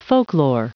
Prononciation du mot folklore en anglais (fichier audio)
Prononciation du mot : folklore